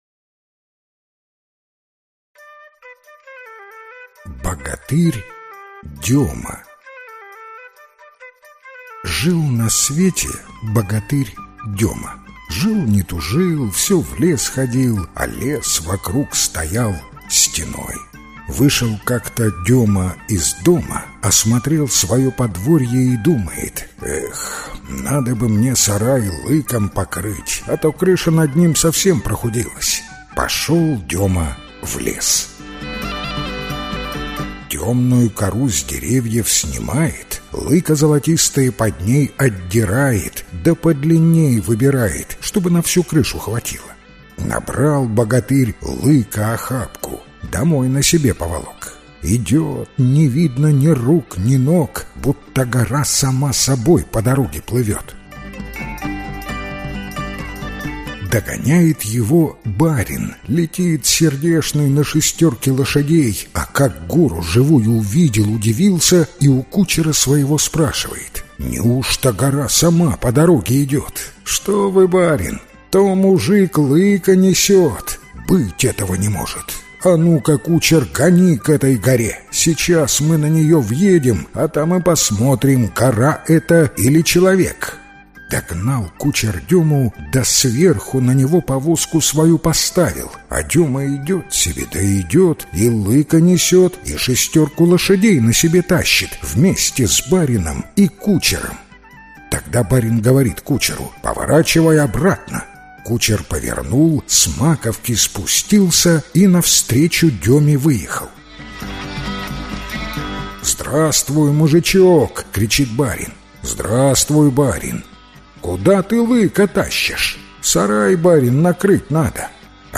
Аудиокнига Белорусские сказки | Библиотека аудиокниг
Прослушать и бесплатно скачать фрагмент аудиокниги